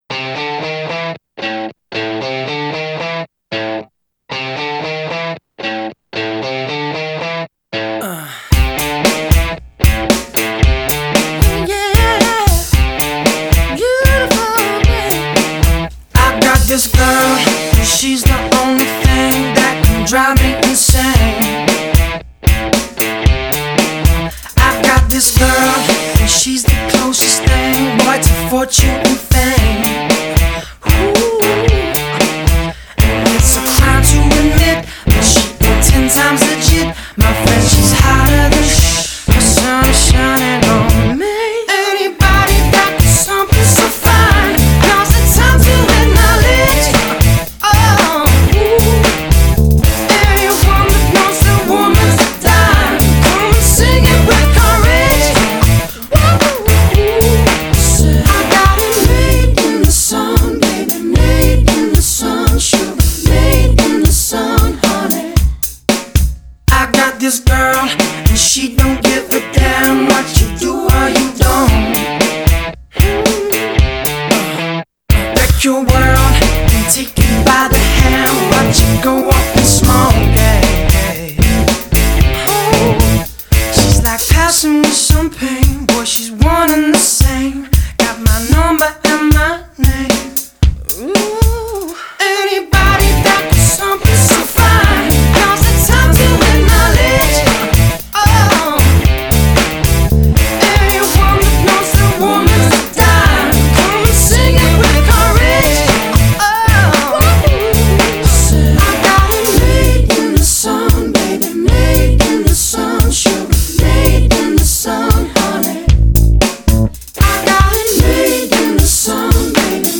Genre: Soundtrack.